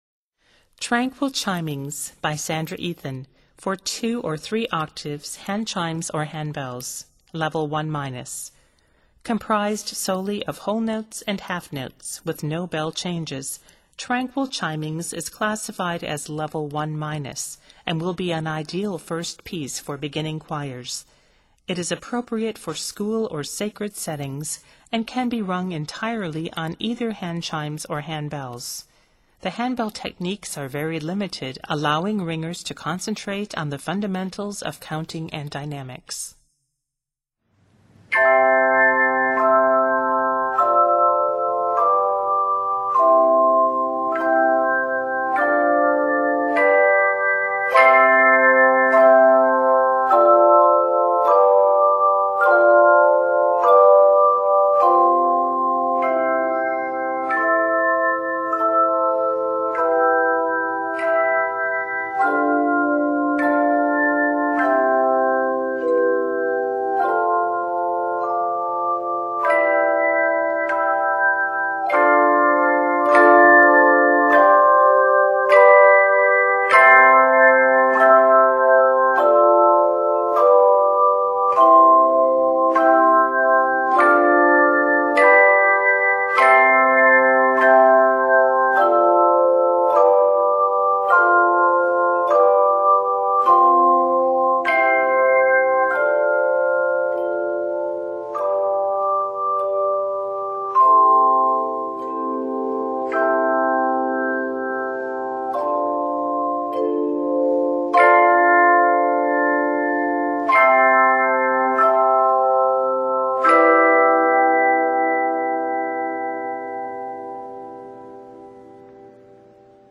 This easy 32-measure composition is written in C Major.